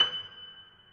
Steinway_Grand